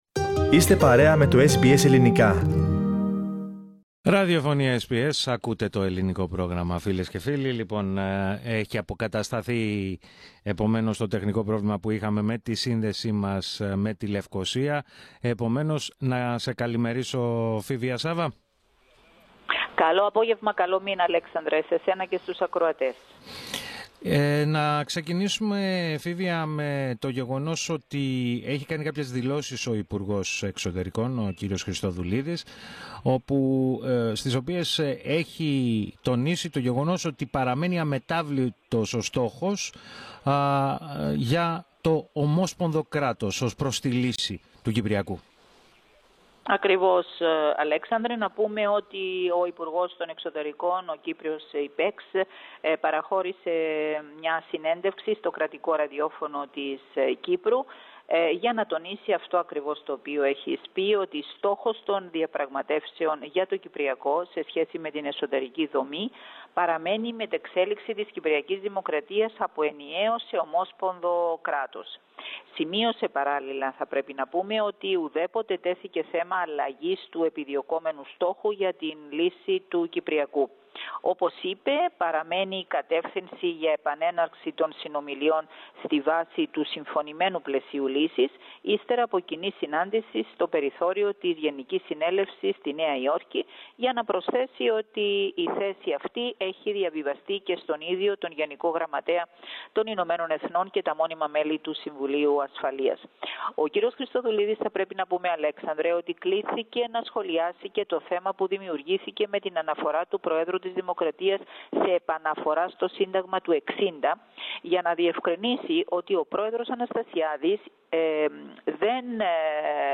The government's objectives for the negotiations on the Cyprus problem were outlined in an interview with the Foreign Minister, Nikos Christodoulides.